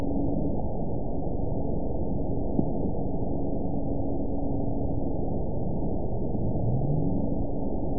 event 922869 date 04/29/25 time 15:23:30 GMT (7 months ago) score 9.43 location TSS-AB02 detected by nrw target species NRW annotations +NRW Spectrogram: Frequency (kHz) vs. Time (s) audio not available .wav